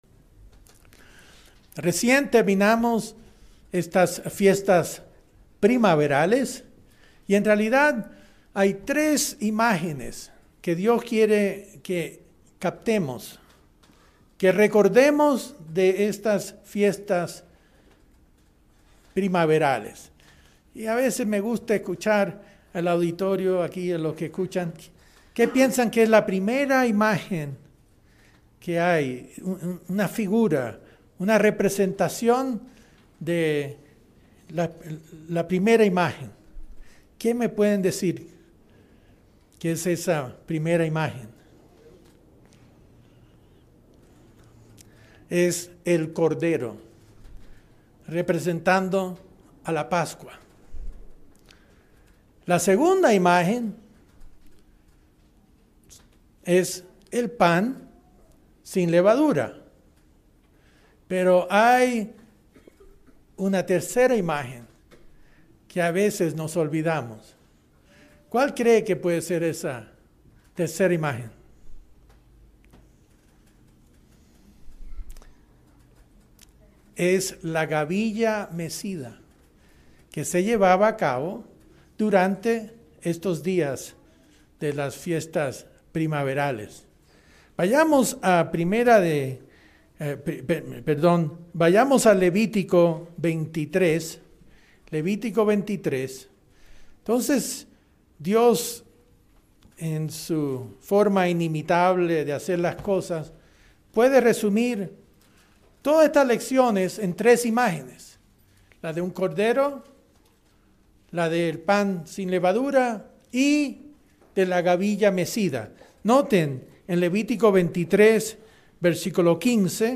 Mensaje entregado el 7 de abril de 2018.